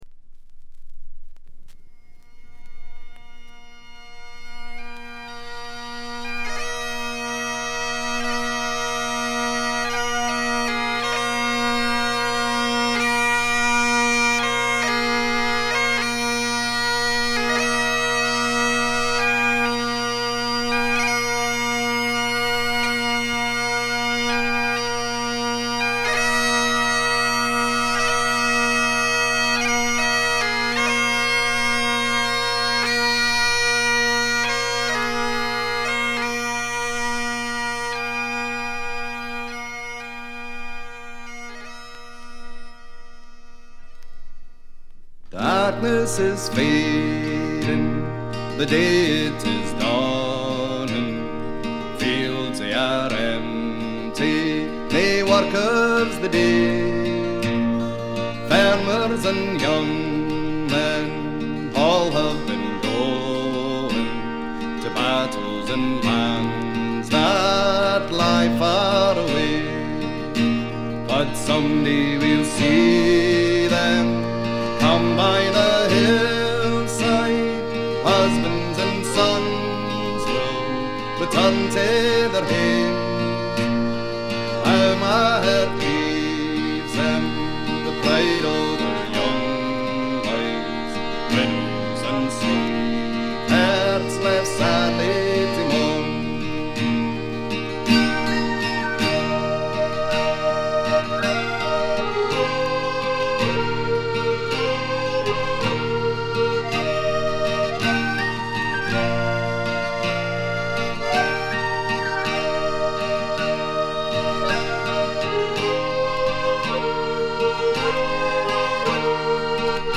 わずかなチリプチ程度。
スコットランドのトラッド・グループ
ギター、笛、アコーディオン、パイプ等が織りなす美しい桃源郷のような世界が展開されます。
試聴曲は現品からの取り込み音源です。
vocals, mandola, mandoline, whistle, guitar
vocals, banjo, guitar
accordion, Highland pipes, keyboards